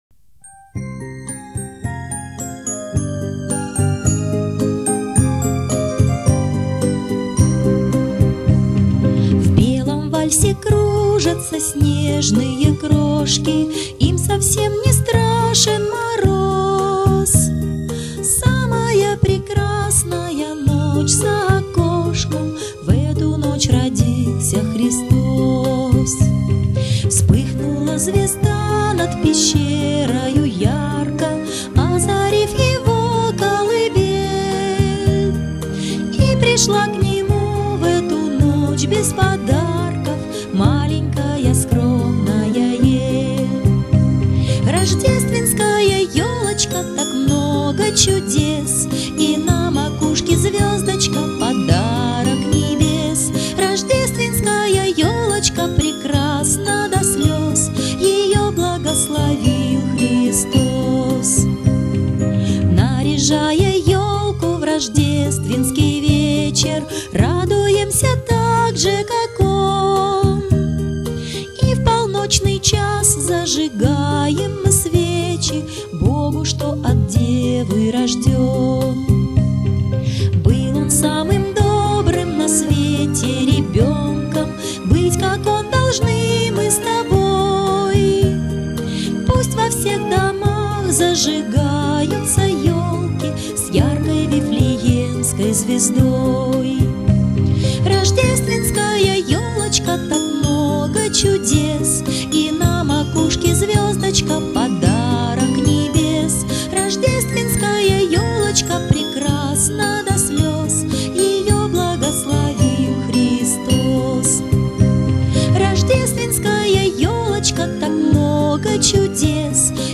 Звучит фонограмма песни